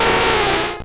Cri de Nidoqueen dans Pokémon Or et Argent.